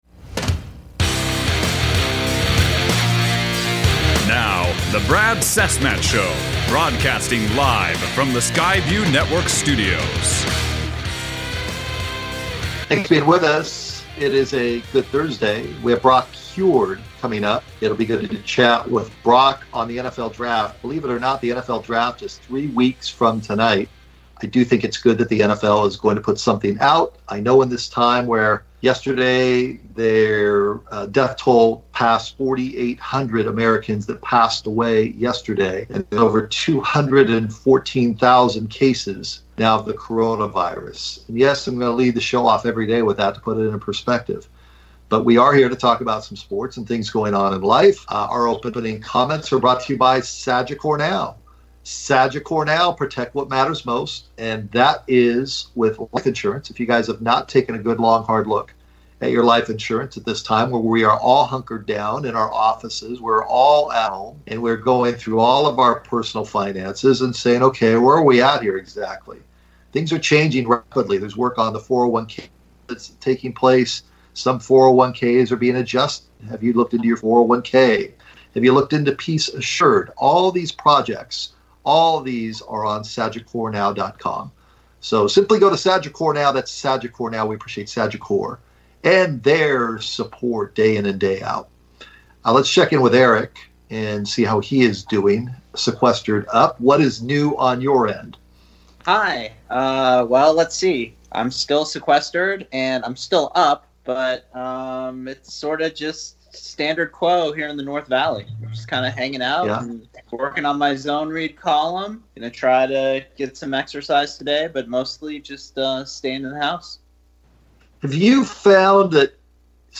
What is the best way to mix up your “daily routine” as we continue to self-quarantine. FOX College Football Analyst and good friend of the show Brock Huard (10:36 on podcast) Skyped in from Seattle to talk about the quarterbacks in this draft class, as well as some NFL news and notes.